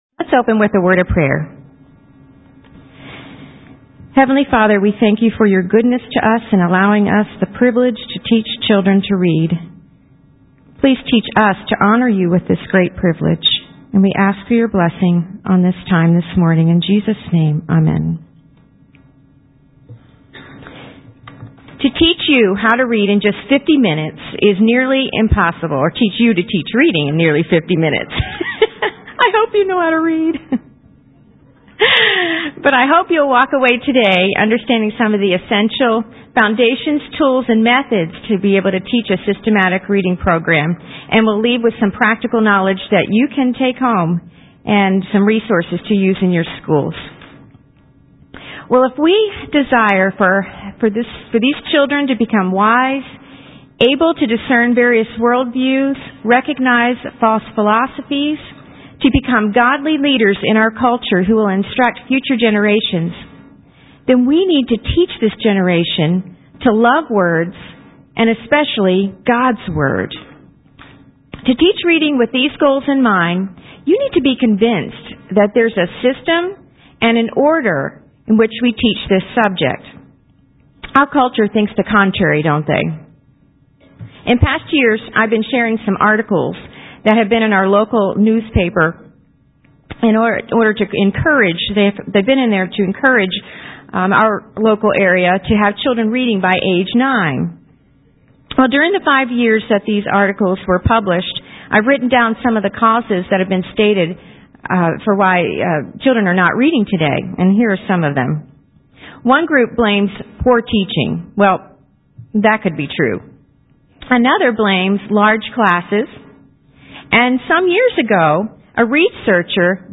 2003 Workshop Talk | 0:58:11 | K-6, Literature